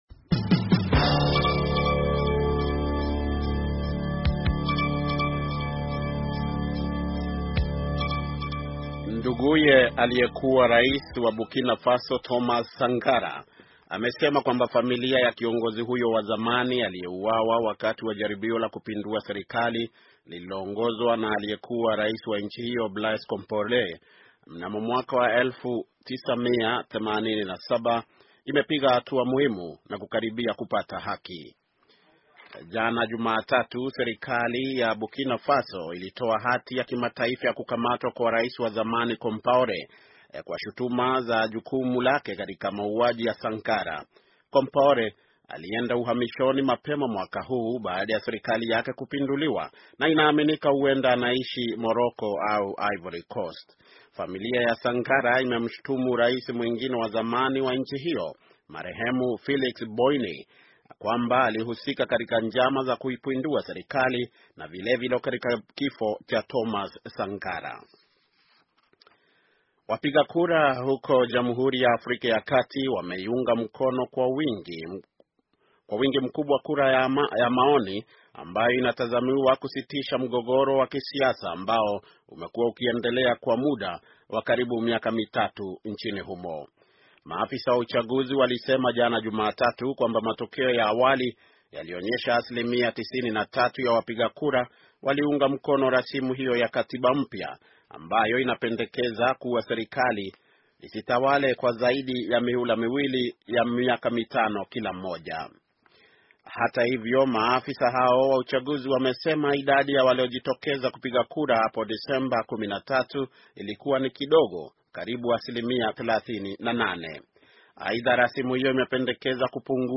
Taarifa ya habari - 6:27